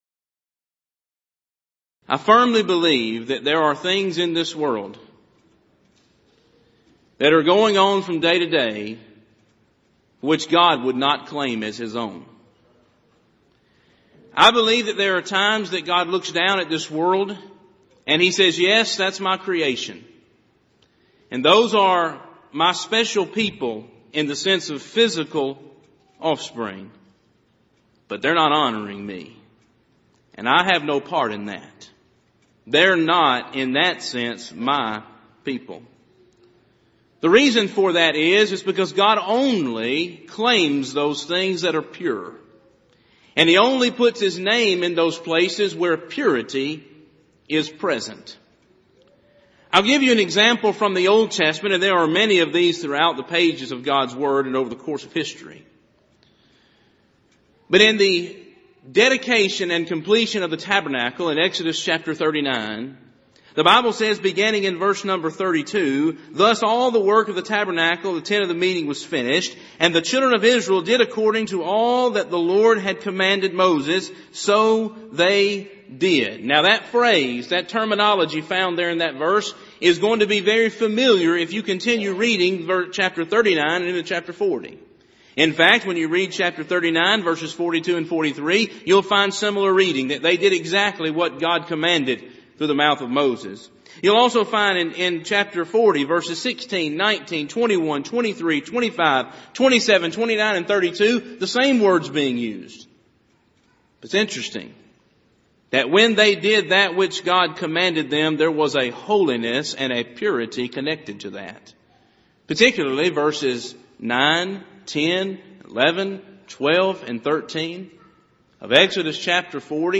Event: 28th Annual Southwest Lectures Theme/Title: Honoring Christ: Calling For Godly Homes
lecture